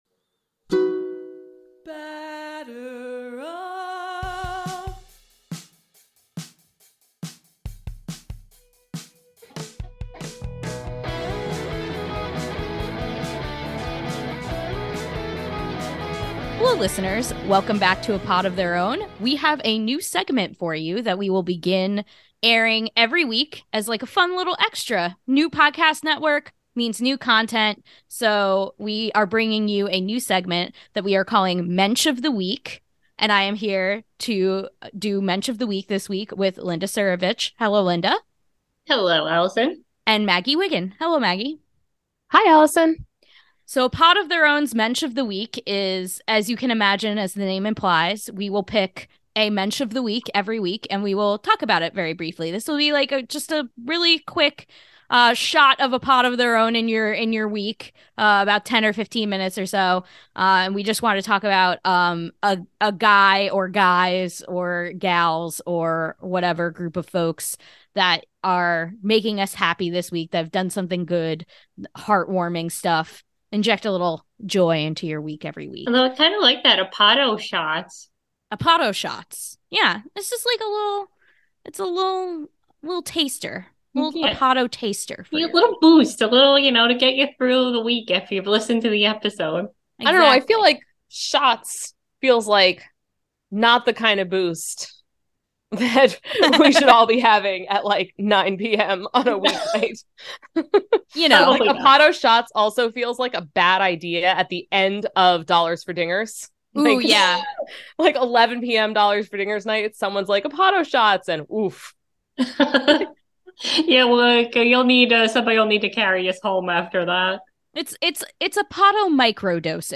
Welcome back to A Pod of Their Own, an all-women led Home Run Applesauce podcast where we talk all things Mets, social justice issues in baseball, and normalize female voices in the sports podcasting space.